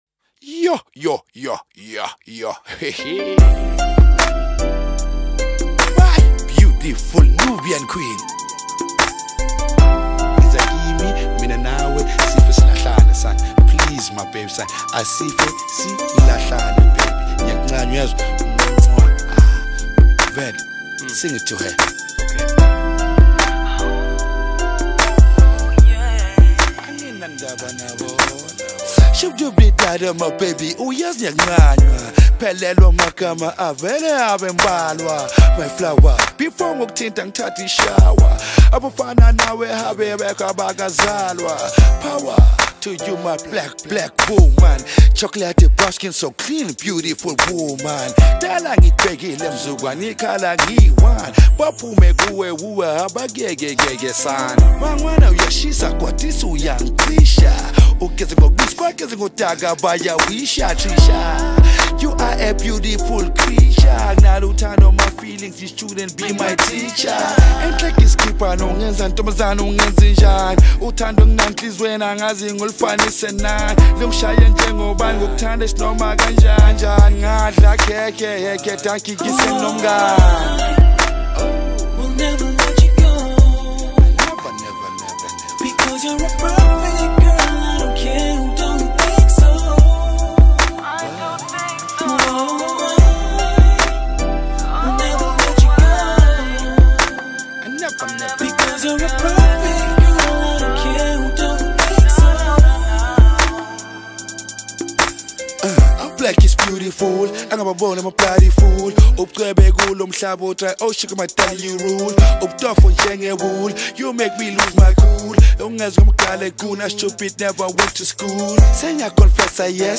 is a South African Kwaito/Hip hop MC from Soweto.